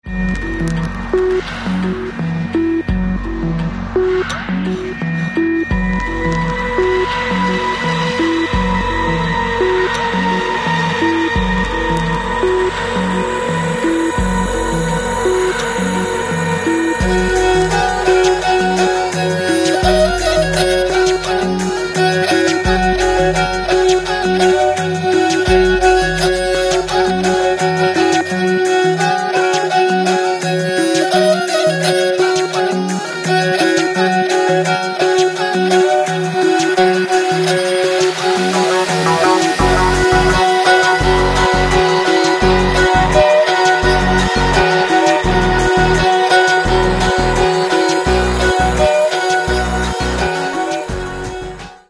Catalogue -> Rock & Alternative -> Electronic Alternative